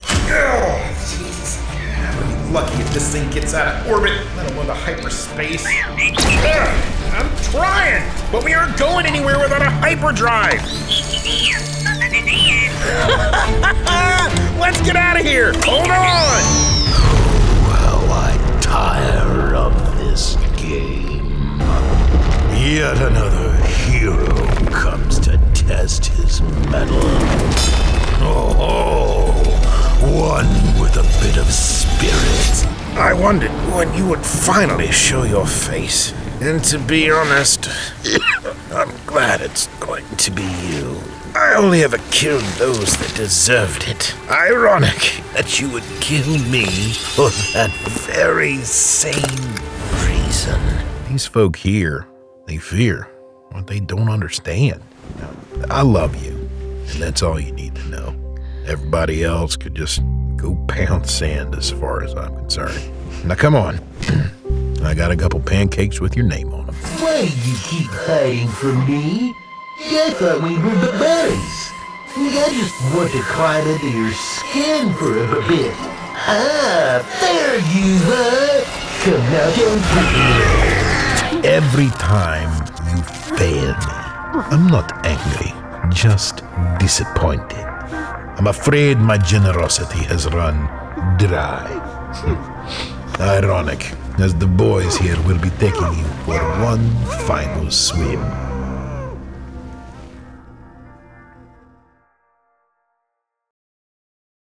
My voice is youthful, engaging, and upbeat, with a clear and lively tone. It has a warm, approachable quality that feels friendly and conversational.